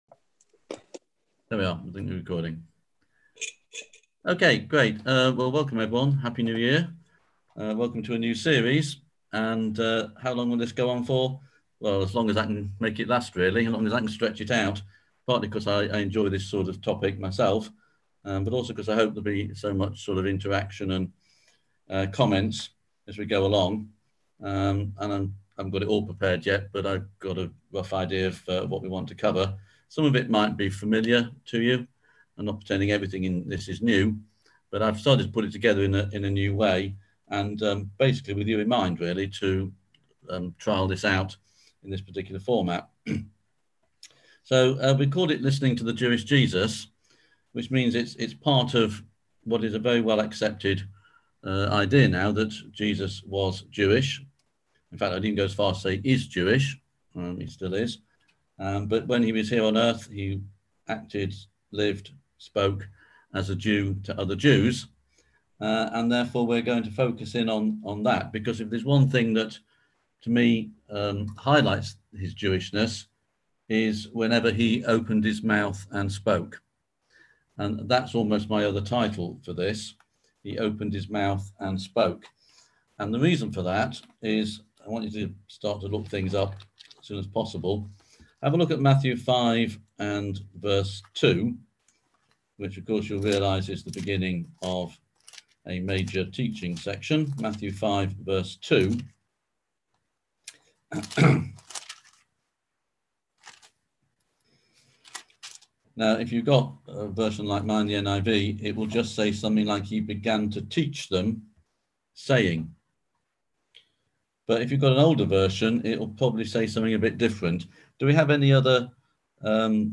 On JANUARY 7th at 7pm – 8:30pm on ZOOM